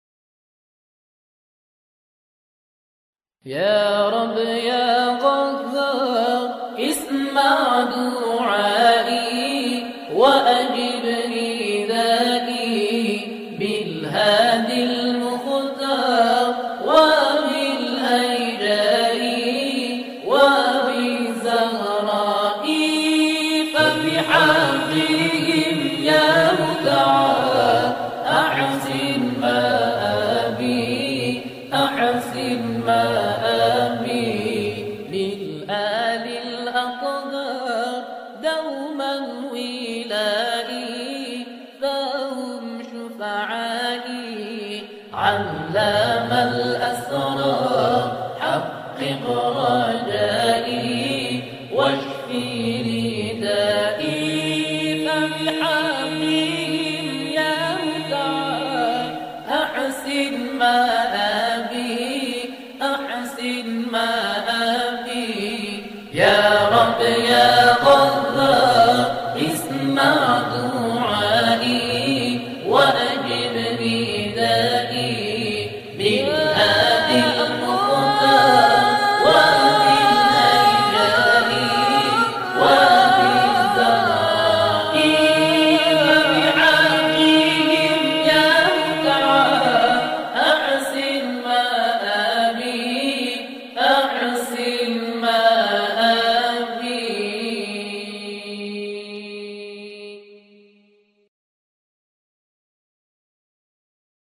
سرودهای ماه رمضان
گروهی از همخوانان